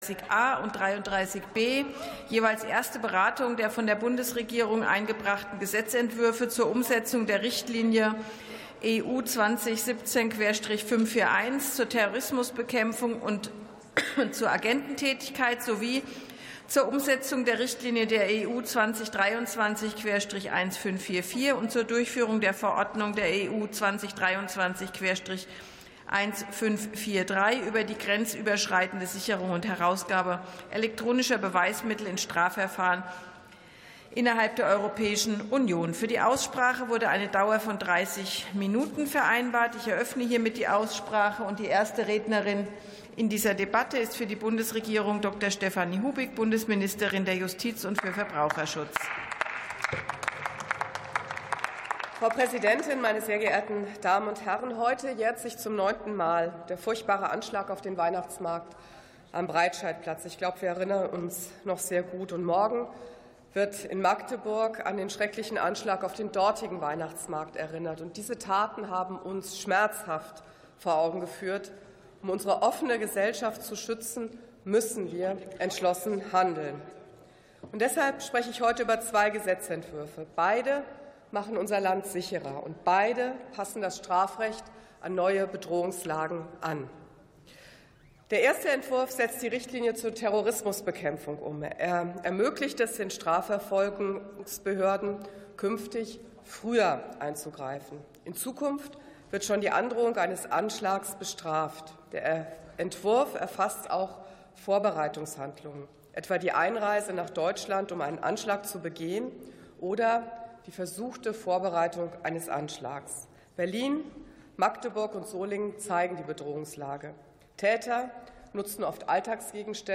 51. Sitzung vom 19.12.2025. TOP 33: EU-Richtlinie zur Terrorismus- und Kriminalitätsbekämpfung ~ Plenarsitzungen - Audio Podcasts Podcast